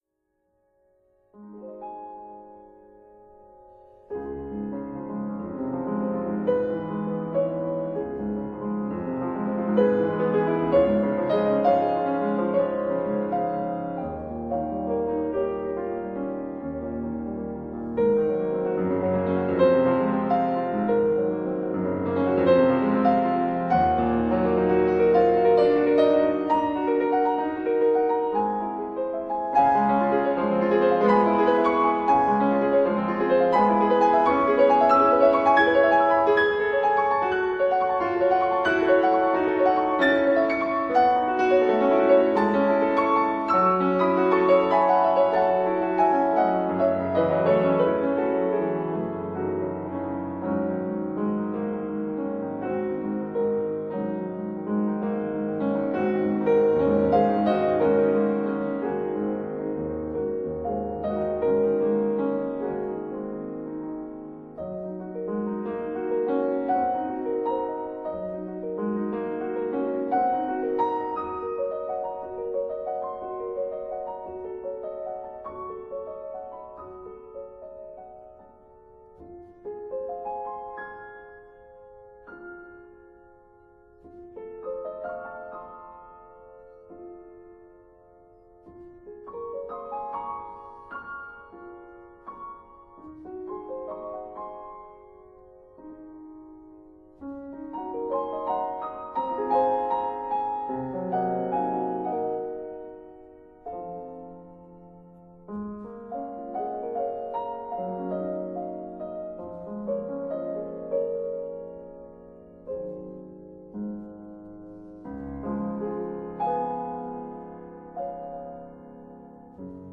* Audio 1 in der üblichen genormten Stimmung  A = 440 Hz
Hörprobe A = 440Hz
hoerprobe_a___440_hz.mp3